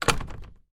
На этой странице собраны реалистичные звуки пластиковых дверей: от плавного открывания до резкого захлопывания.
Звук захлопывания пластиковой двери в аптеке